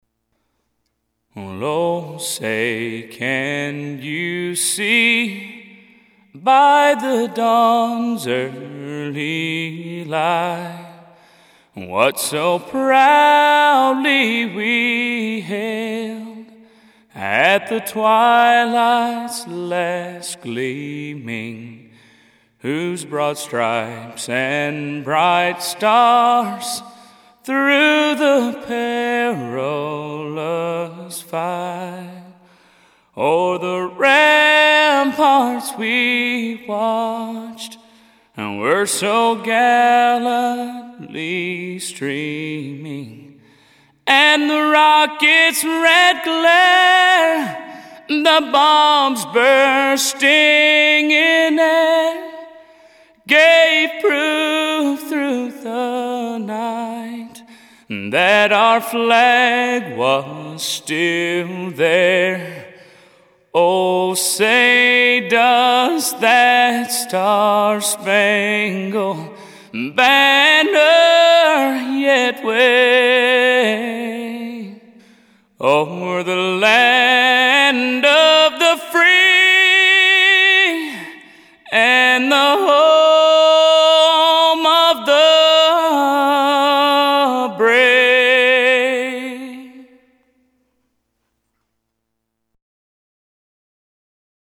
1:34 stereo